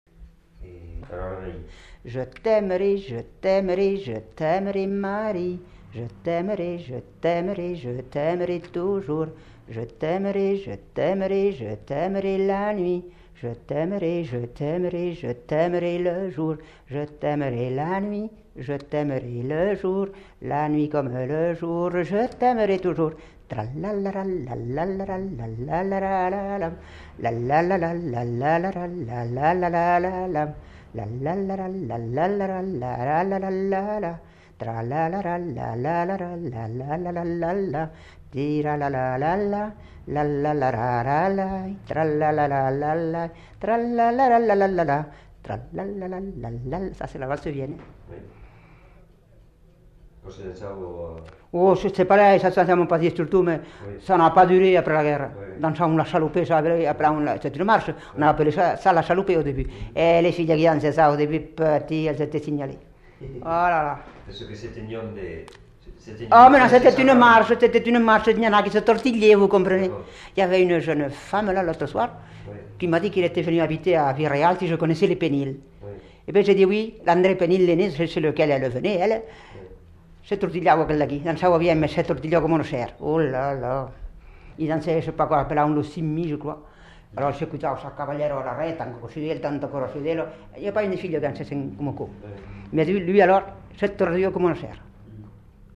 Aire culturelle : Haut-Agenais
Lieu : Castillonnès
Genre : chant
Effectif : 1
Type de voix : voix de femme
Production du son : chanté
Danse : varsovienne